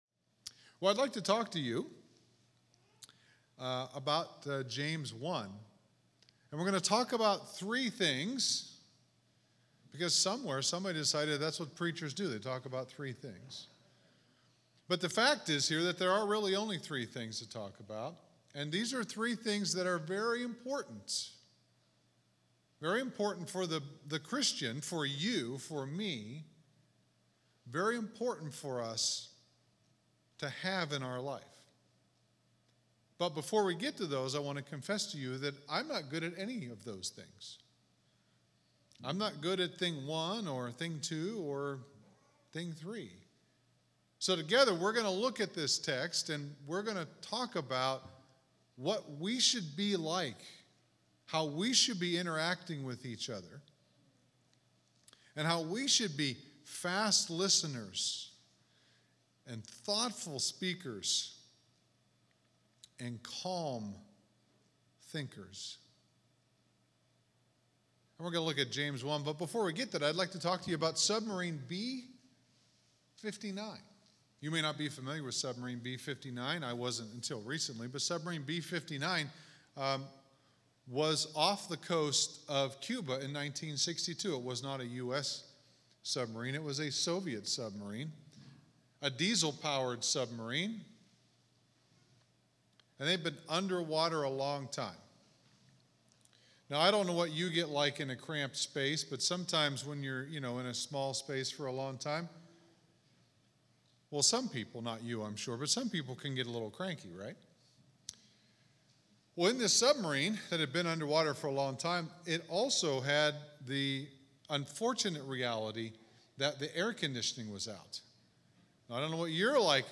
A message from the series "Stand Alone Messages."